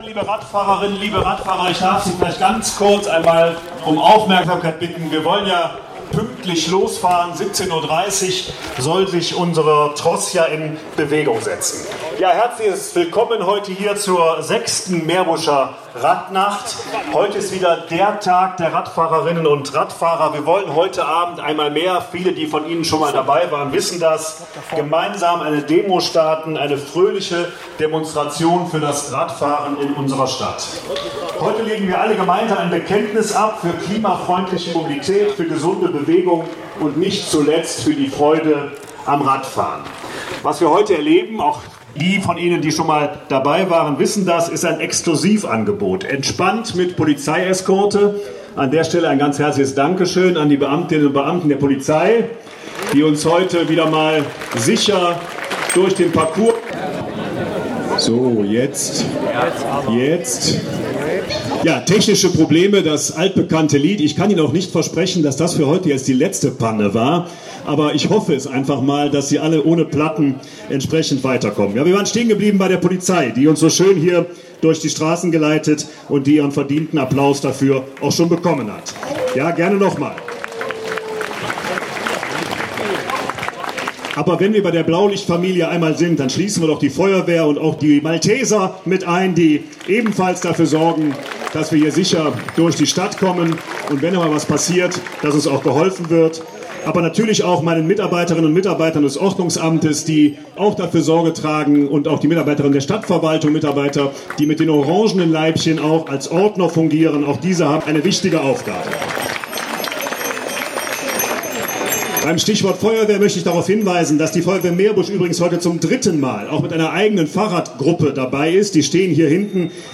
Der Bürgermeister Christian Bommers begrüßte die FahradfreundInnen, ...
Die Auftaktrede von Christian Bommers (Audio 1/1) [MP3]